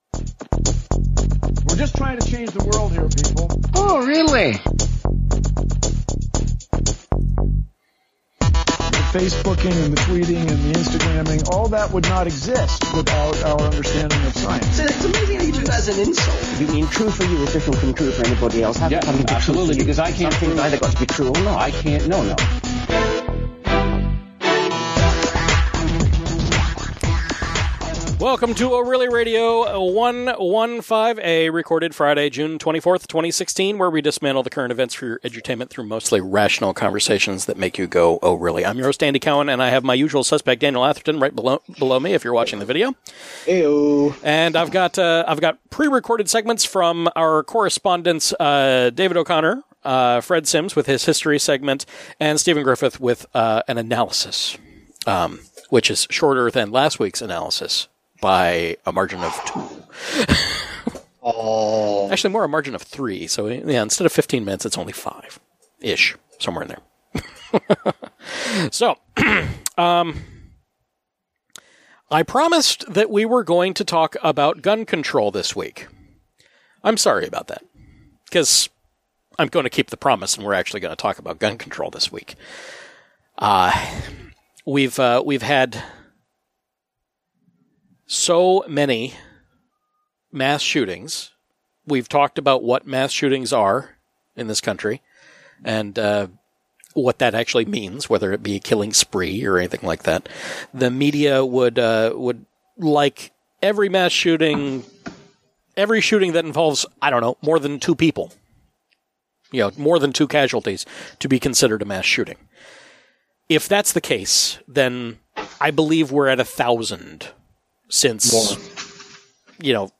Live every Friday night at about 9pm